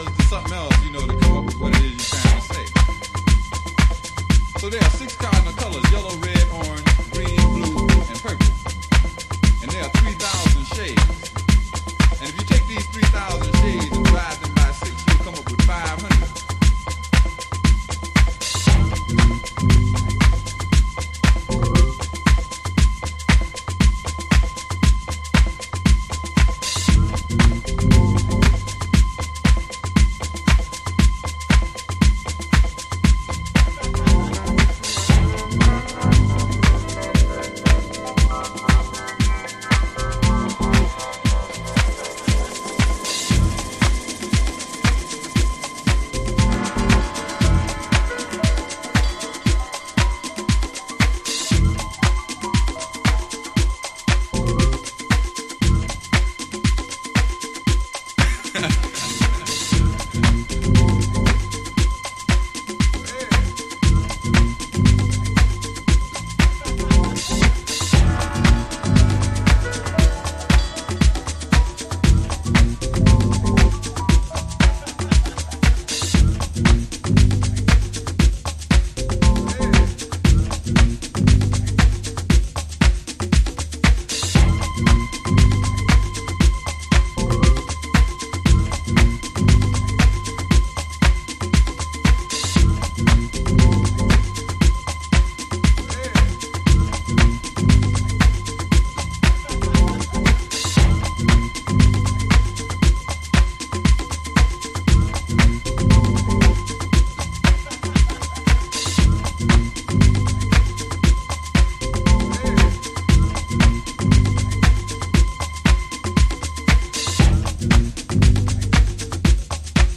サンプリングメインでつくり上げられた、粒子浮き立つRAW HOUSE TRACKS。